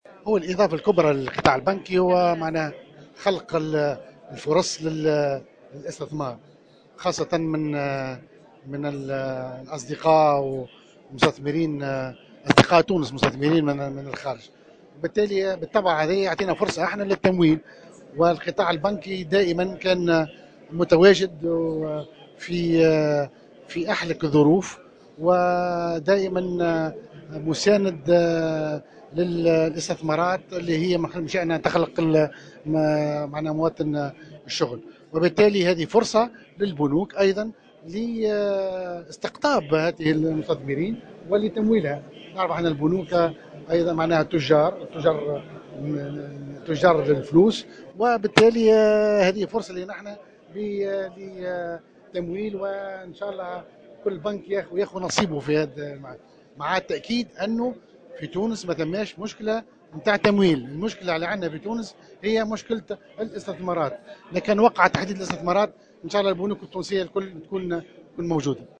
على هامش مشاركته في الندوة